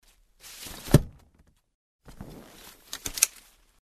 Звуки зонтика
Открыли и закрыли зонт